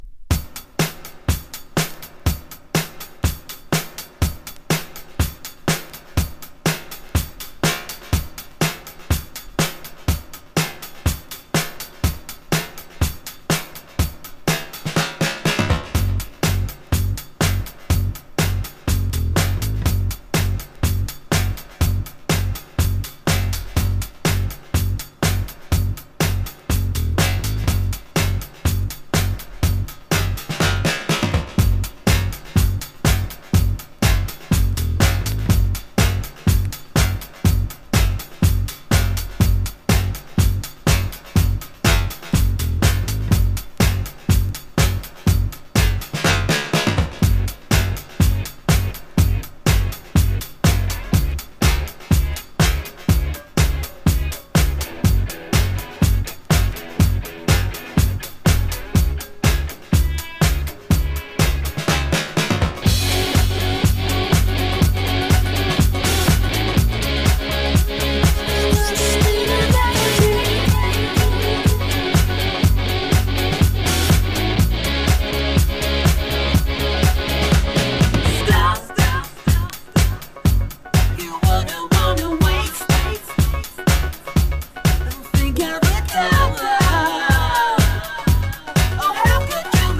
INDIE DANCE
ELECTRO